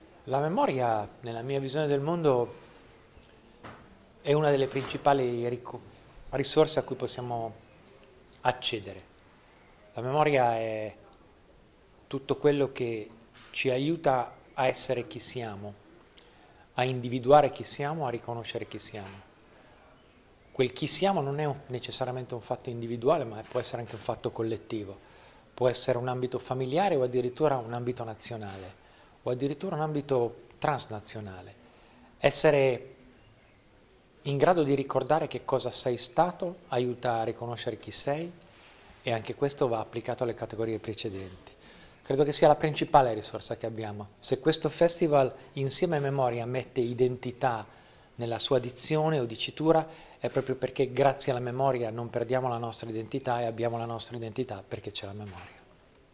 Lo stiamo scoprendo in questi giorni intervistando alcuni degli ospiti della seconda edizione del Memoria Festival di Mirandola:
Abbiamo intervistato anche il giornalista e commentatore sportivo Federico Buffa, ecco la sua definizione di Memoria: